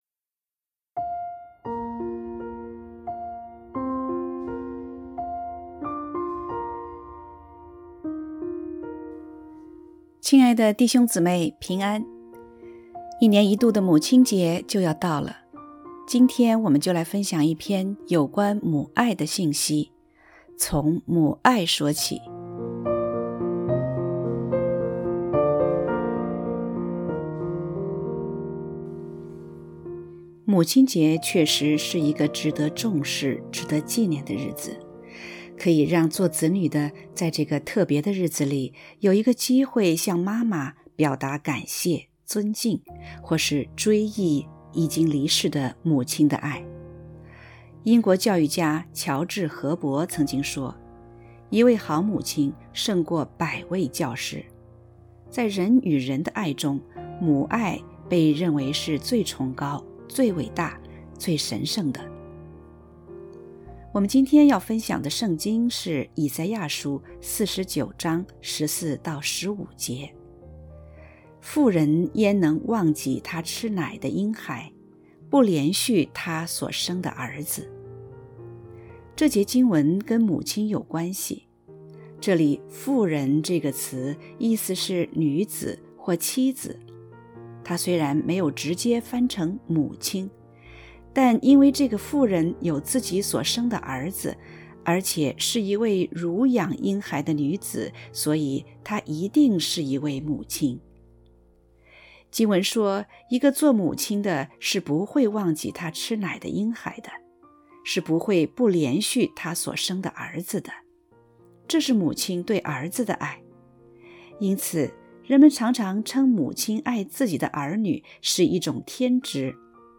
（合成）从母爱说起.mp3